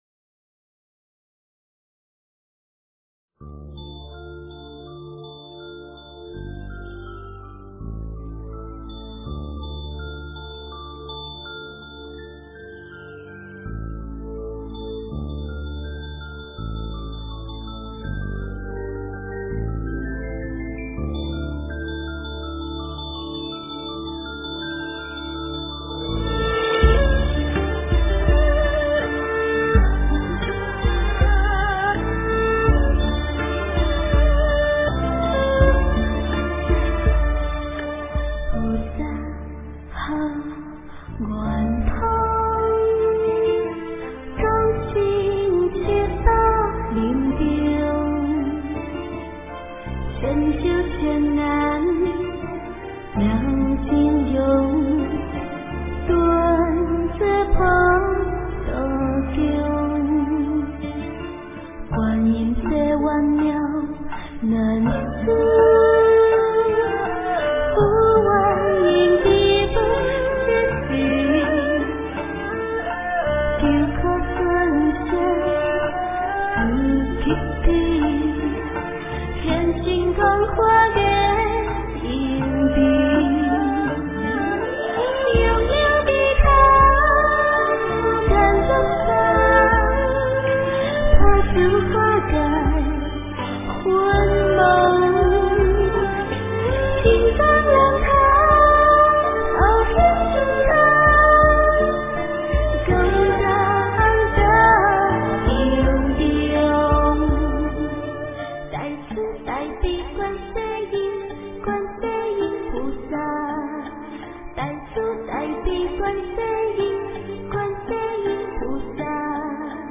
标签: 佛音凡歌佛教音乐